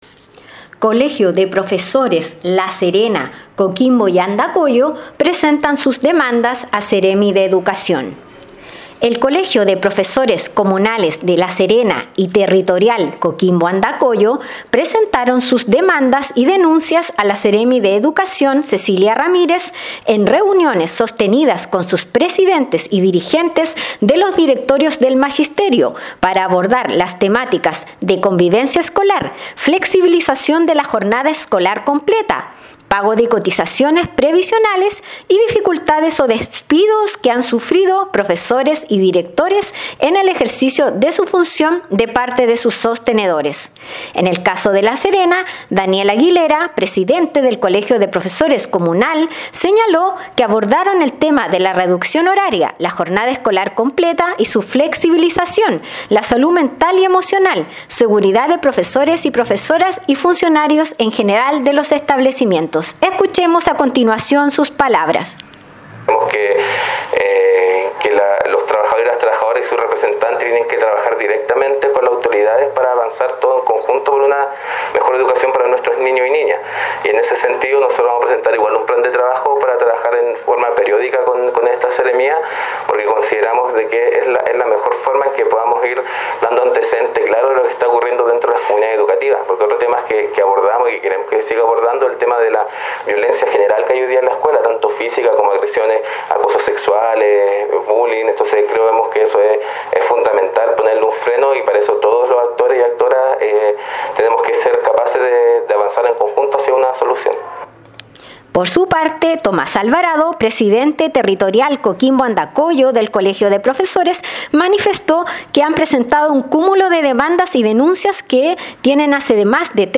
Despacho-Radial-Colegios-de-Profesores-presentan-sus-demandas-a-Seremi_.mp3